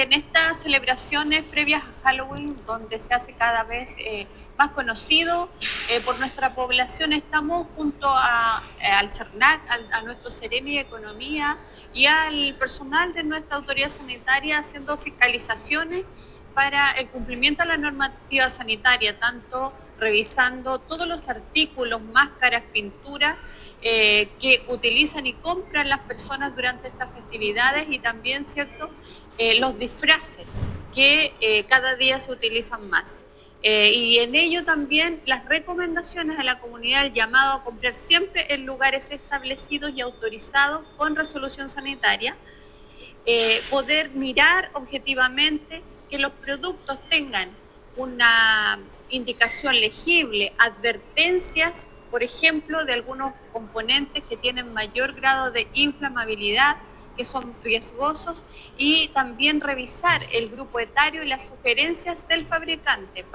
Finalmente, la seremi de Salud, Karin Solís, señaló que los fiscalizadores están revisando todos los artículos, mascaras, pinturas que utilizan y compran las personas en esta festividad, así como también los disfraces que cada día se utilizan más.